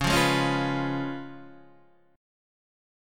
C#7 chord